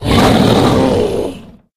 izlome_attack_8.ogg